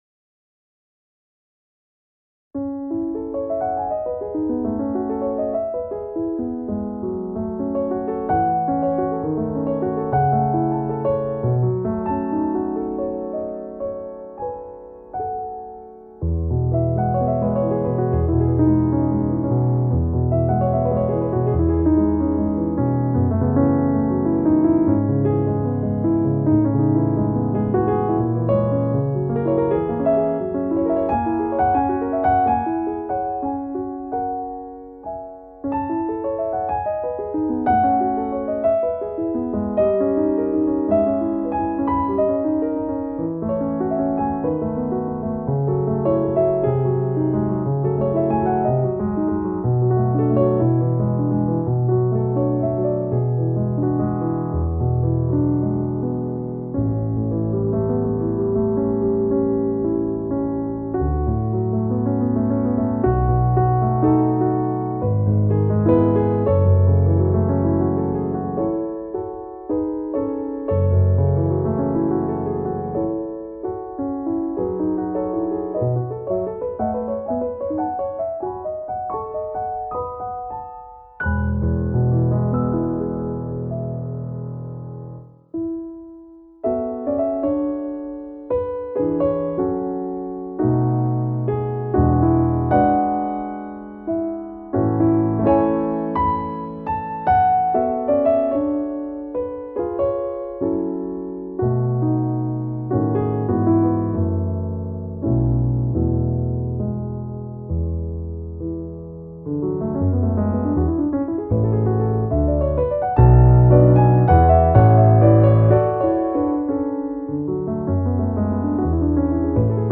• Top-end Nord Stage Piano used as standard in the events industry
Solo Jazz and Contemporary Pianist for Hire